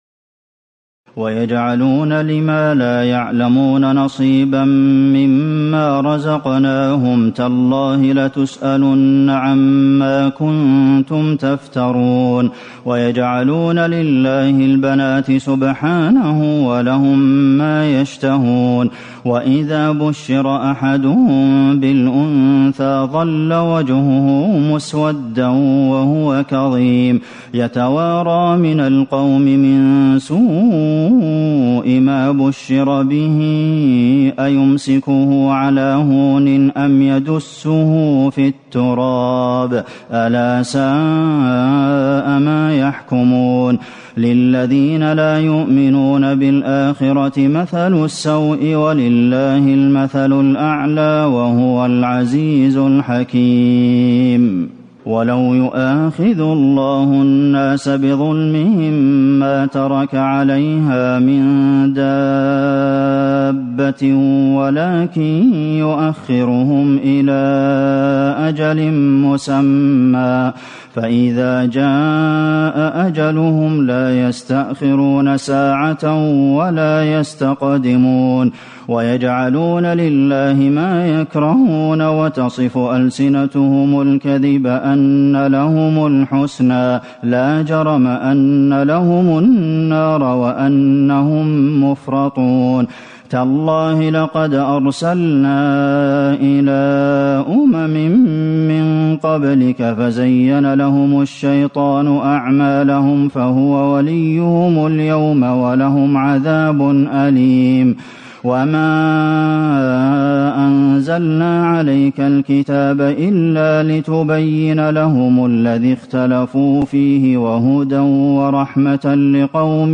تراويح الليلة الثالثة عشر رمضان 1437هـ من سورة النحل (56-128) Taraweeh 13 st night Ramadan 1437H from Surah An-Nahl > تراويح الحرم النبوي عام 1437 🕌 > التراويح - تلاوات الحرمين